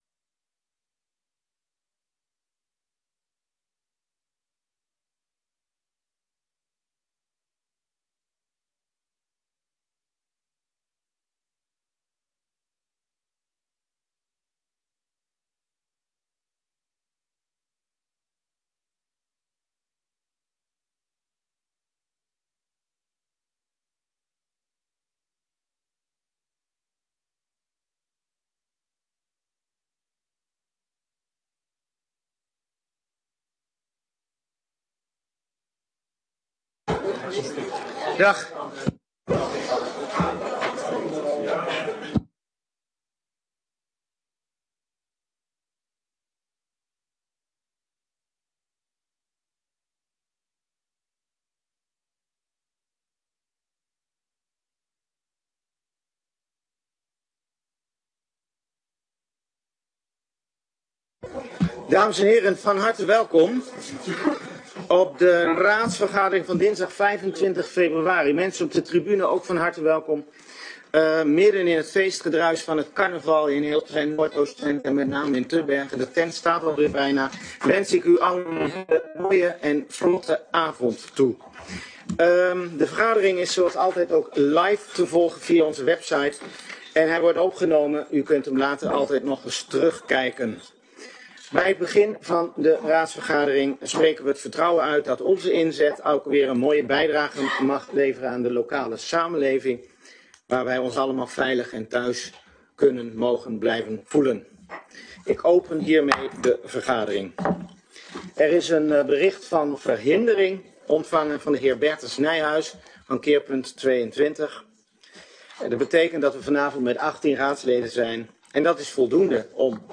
Gemeenteraad Tubbergen 25 februari 2025 19:30:00, Gemeente Tubbergen
Download de volledige audio van deze vergadering